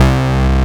BASS06  01-R.wav